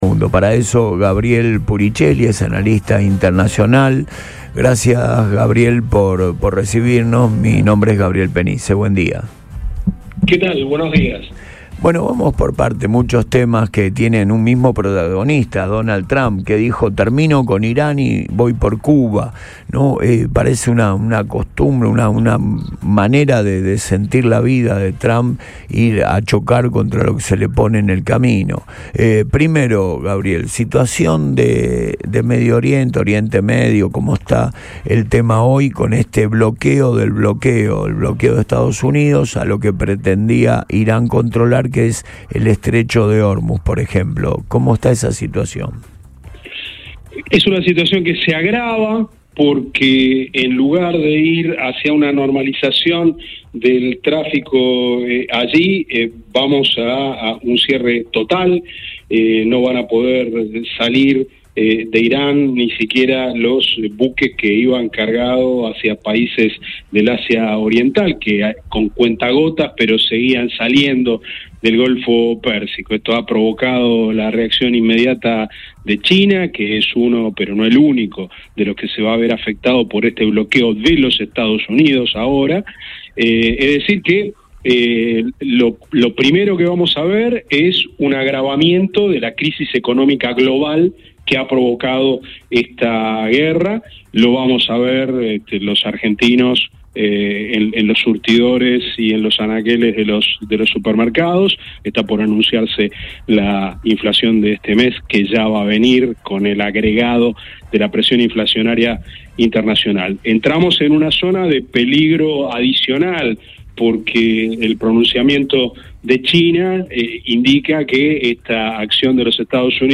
En diálogo con el programa Antes de Todo por Radio Boing